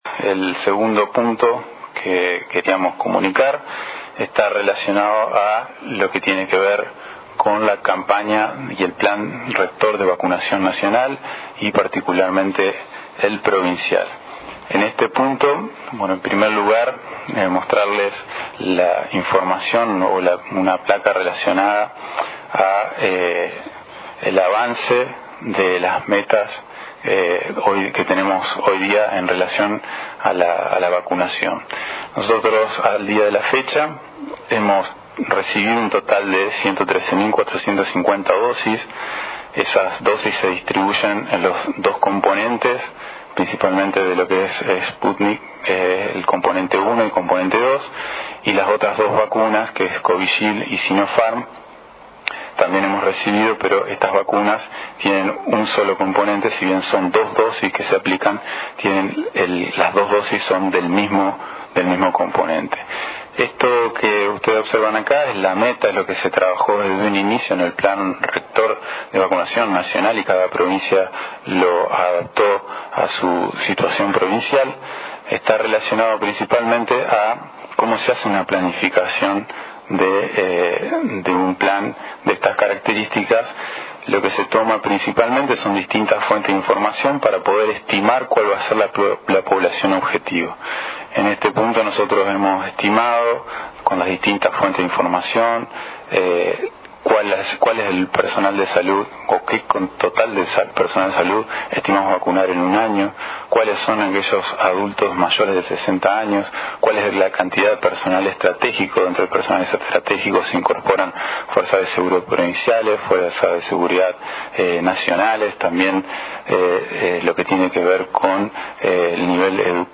A través de una conferencia de prensa virtual, el Director provincial de Epidemiología, Doctor Diego Garcilazo, habló sobre dos temas que atraviesan, el operativo con el coronavirus, que lleva adelante esta gestión provincial.